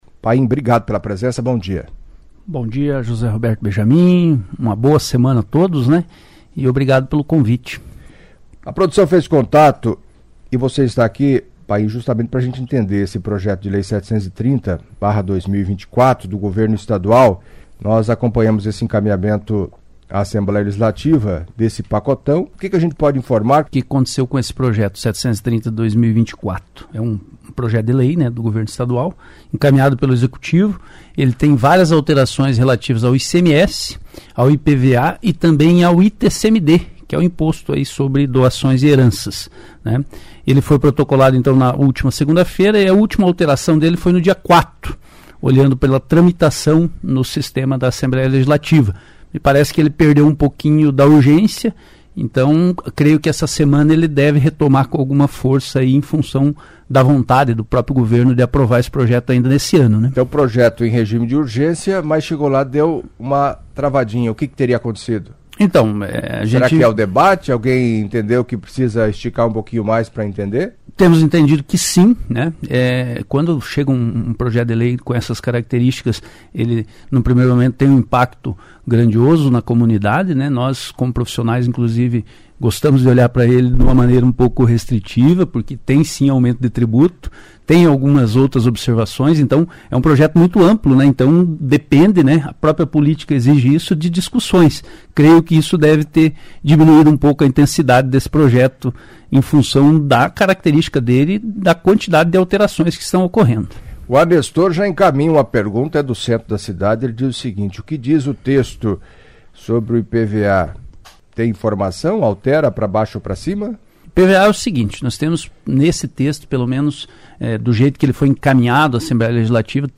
Sobre as mudanças e o projeto do governo do estado encaminhado, recentemente, à Assembleia Legislativa, acompanhe a entrevista